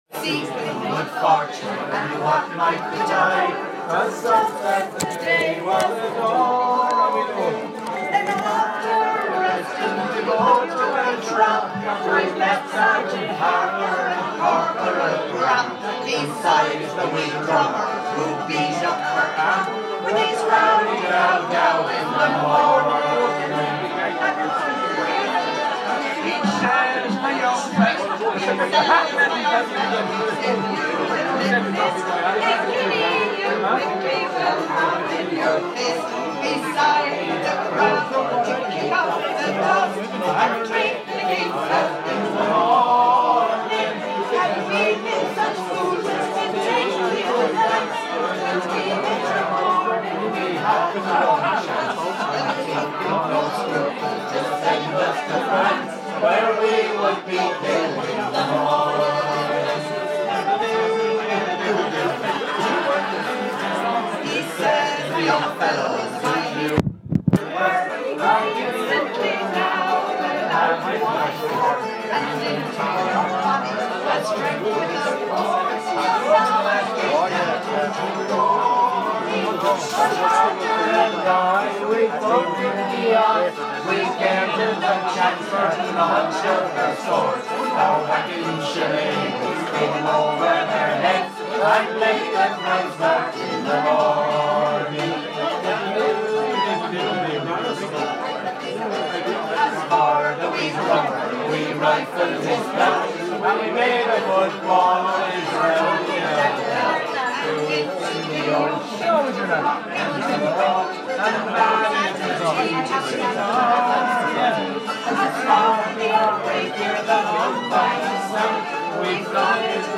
Booze blaas and banter at Imagine Festival Waterford